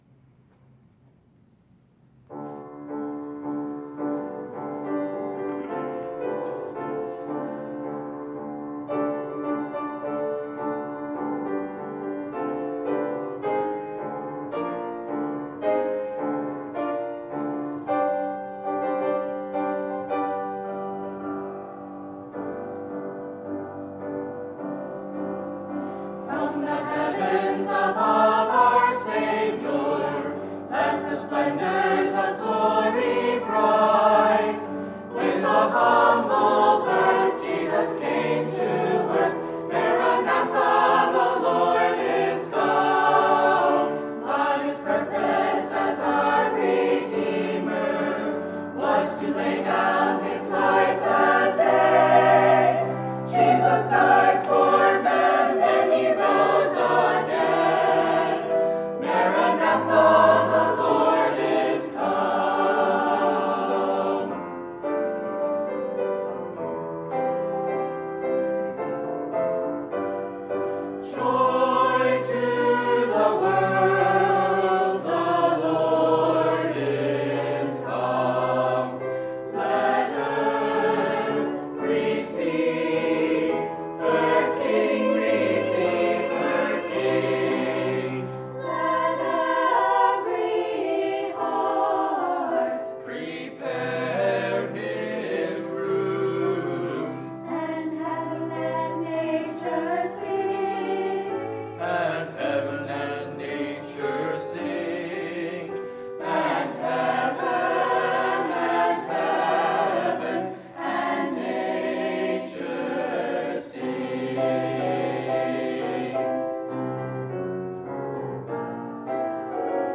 Maranatha! 2025 NHBC Christmas Cantata
Sunday, December 21, 2025 – Sunday AM